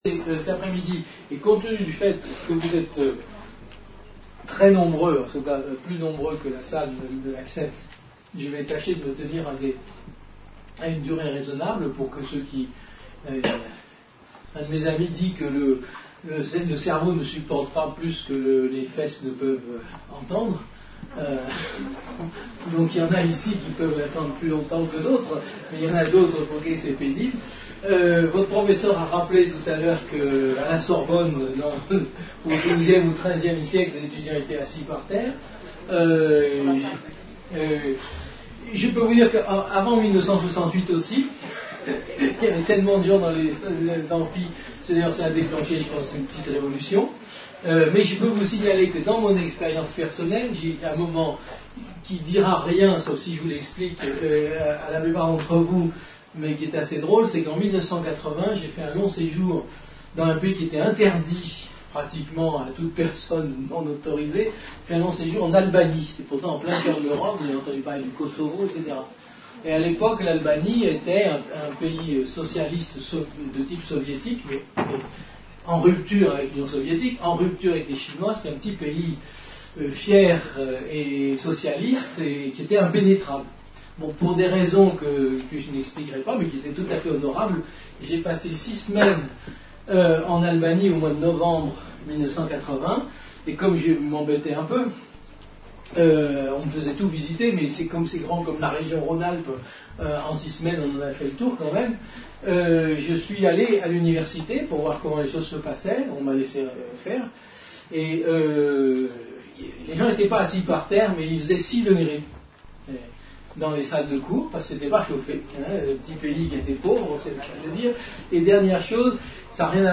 Une conférence de l'UTLS au Lycée avec Yves Michaud (philosophe) en partenariat avec Agrobioscience - L'université des lycéens Lycée Théophile Gautier (Tarbes)